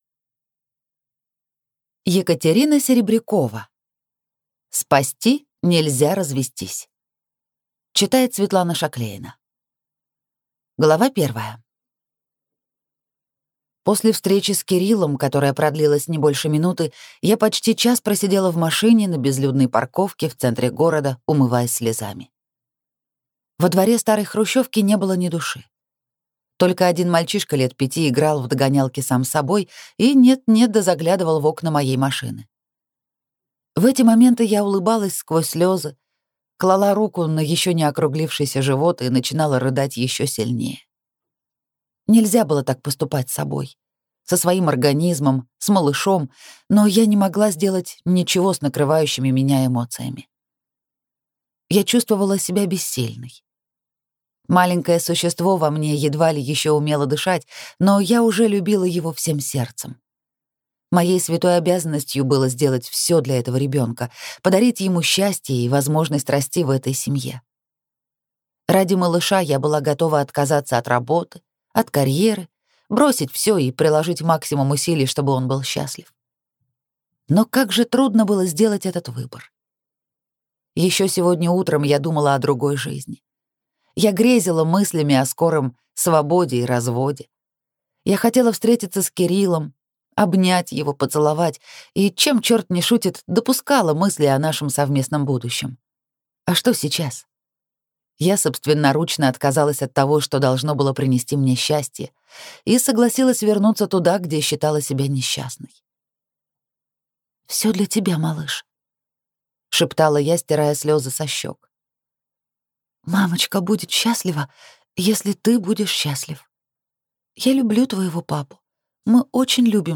Аудиокнига Спасти нельзя развестись | Библиотека аудиокниг